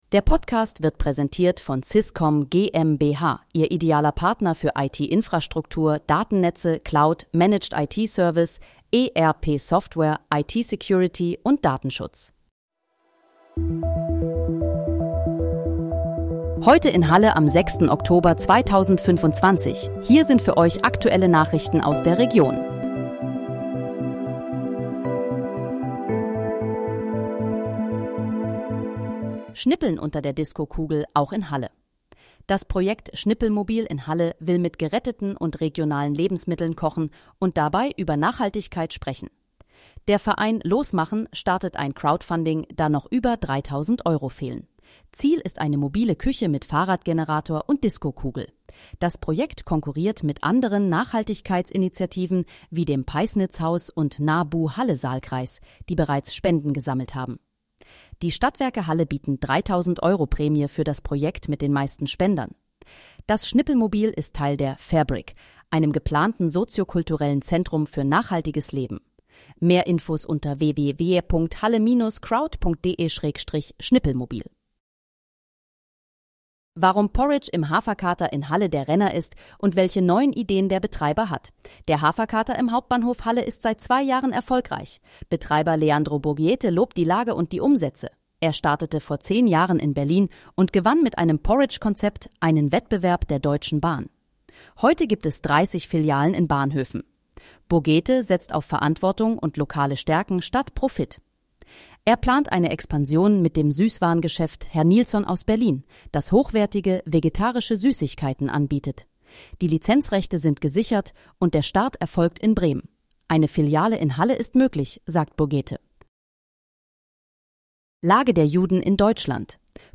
Heute in, Halle: Aktuelle Nachrichten vom 06.10.2025, erstellt mit KI-Unterstützung